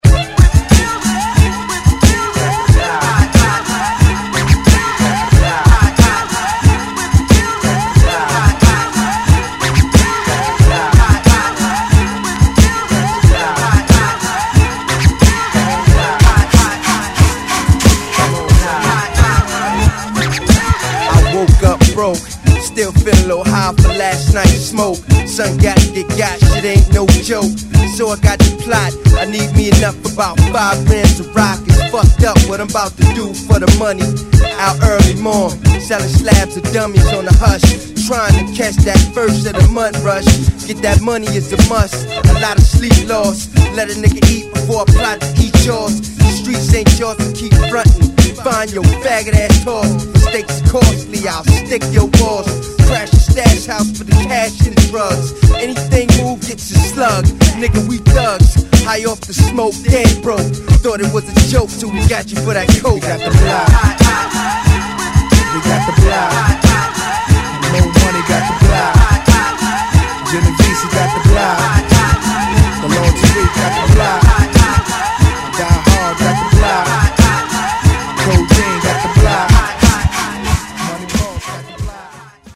ホーム HIP HOP UNDERGROUND 12' & LP M